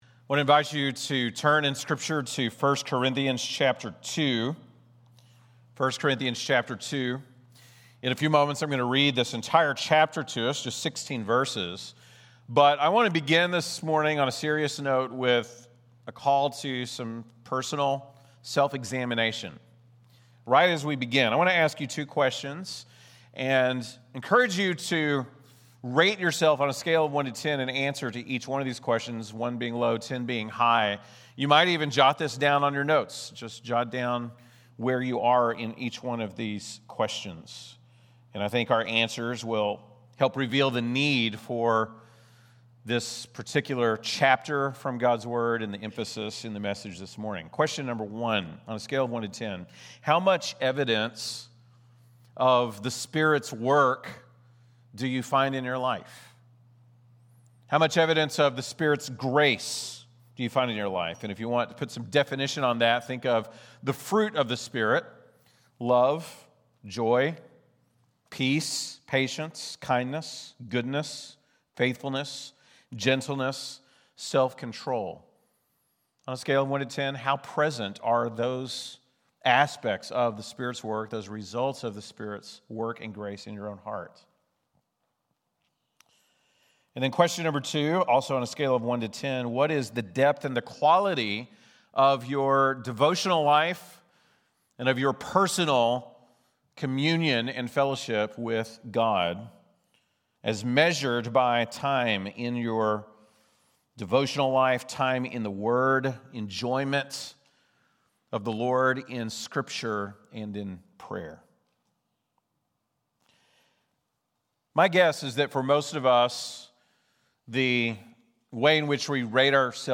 ( Sunday Morning )